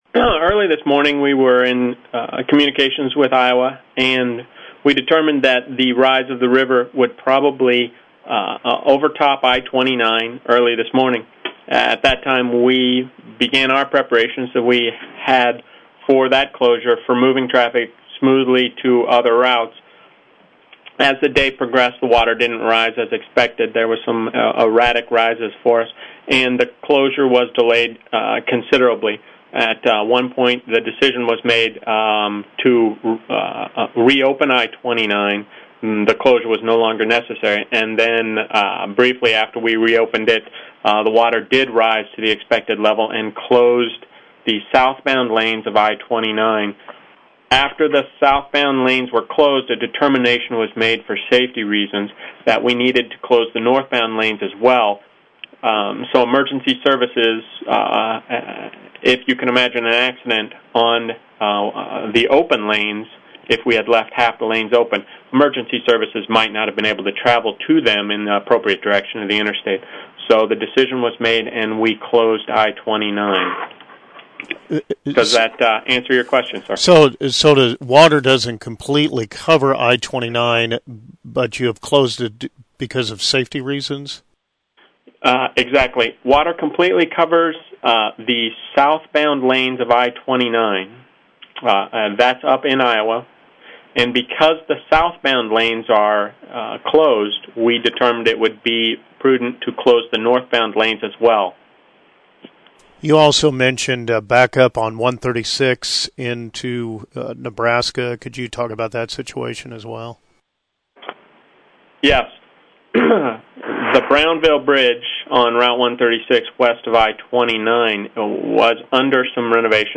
interviews MoDOT/Corps of Engineers on I-29 closure